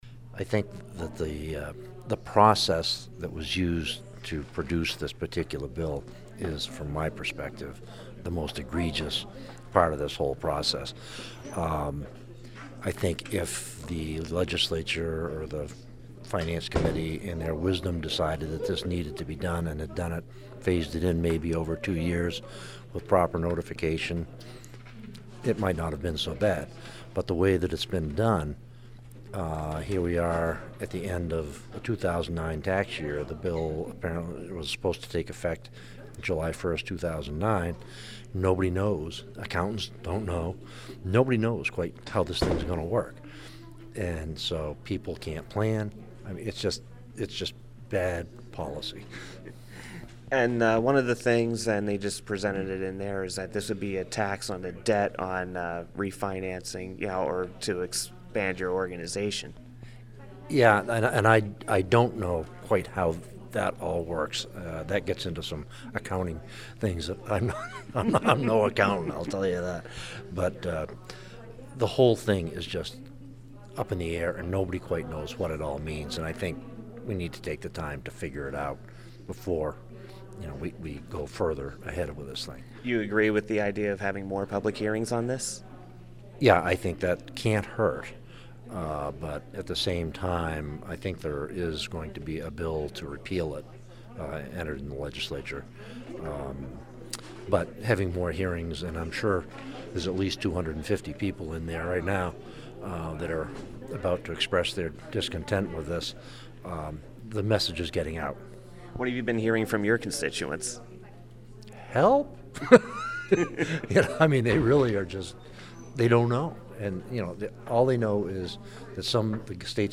The following audio cuts are interviews I did 12/16/09 at the LLC Tax hearing in Concord and played on the show:
Interview- State Rep. David Kidder (R-New London)